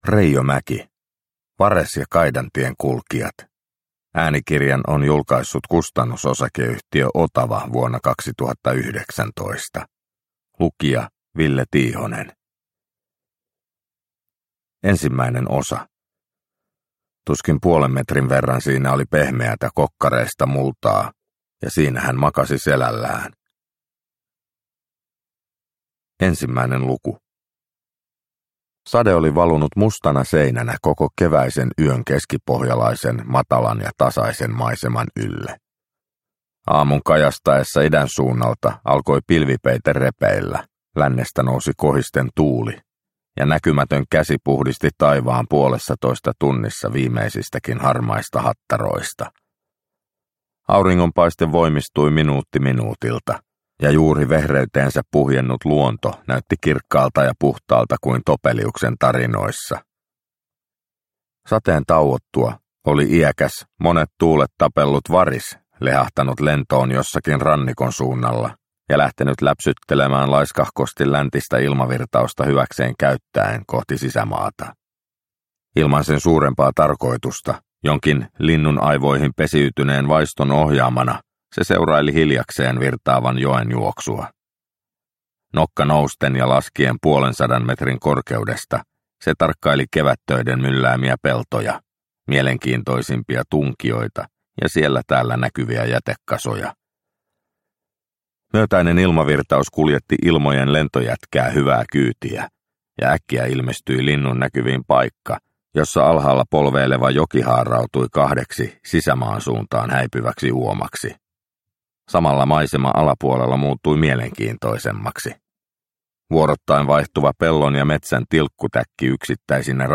Vares ja kaidan tien kulkijat – Ljudbok – Laddas ner